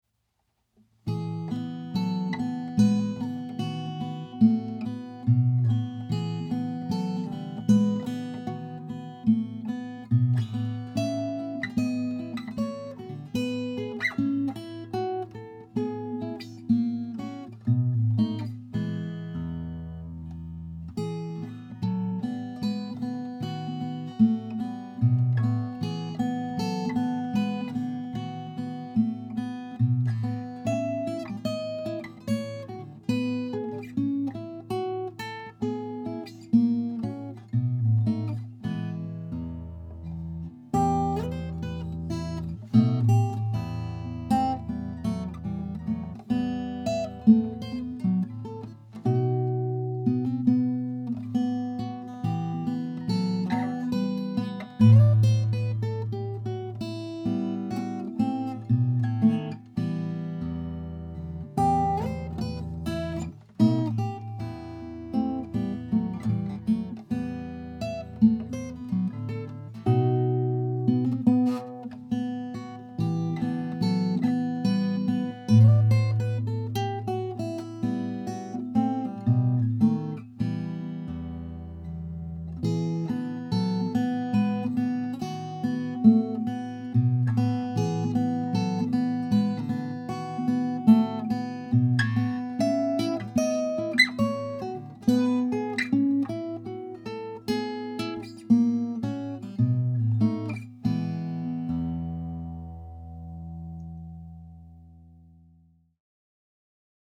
So my first attempt was basically to try to figure out the best placement of the mic to record the acoustic.
The piles don’t let the sound bounce around as much as it might otherwise.
I did a bunch of tests from different distances and angles, and I ended up liking the mic about a foot away, angled down towards the body, at about the 10th fret.
This was one of the first classical pieces I ever tried to play.
It also probably would have been better, with less string noise, if I had a classical nylon string guitar rather than my steel string, but I don’t.  I’ll also admit to making two small timing edits, but since this is also about learning Logic, it’s okay.